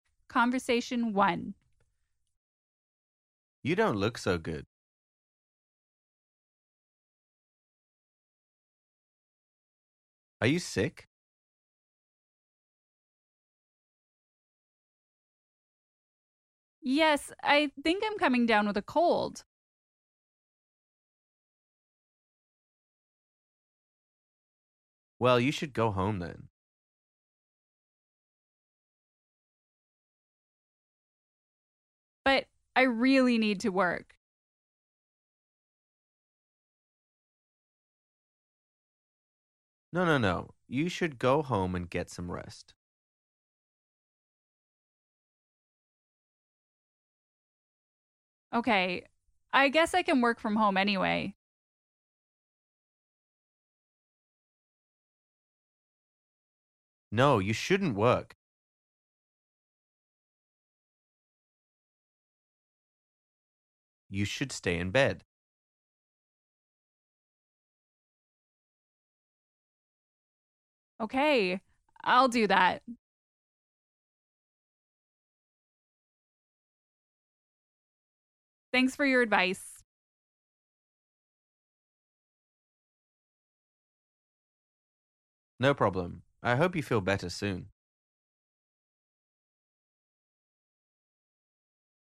Man: You don’t look so good.